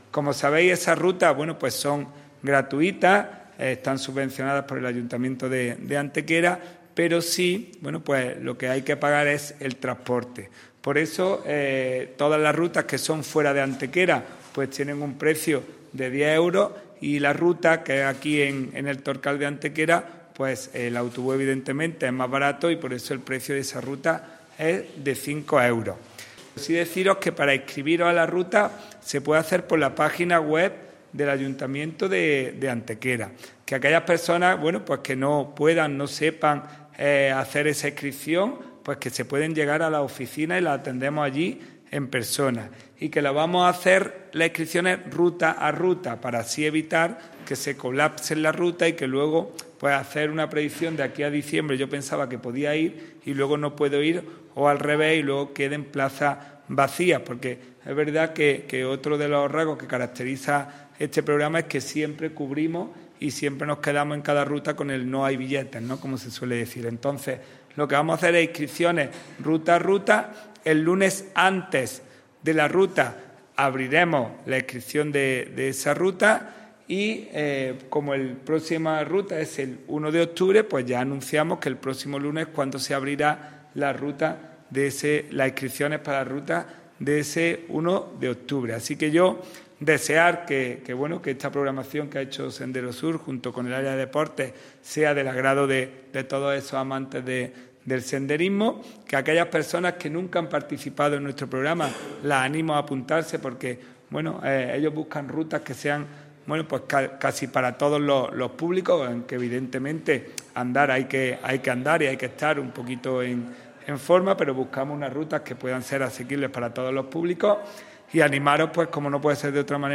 El teniente de alcalde delegado de Deportes, Juan Rosas, ha presentado hoy en rueda de prensa el nuevo programa de Senderismo que se llevará a cabo durante el otoño impulsado por el Área de Deportes del Ayuntamiento de Antequera.
Cortes de voz